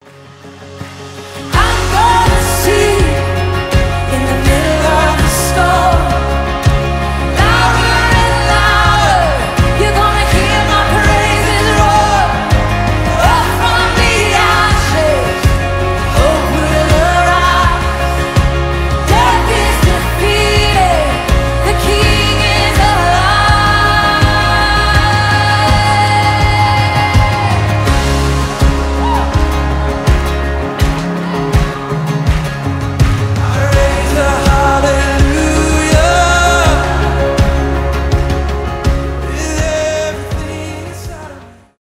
госпел
христианские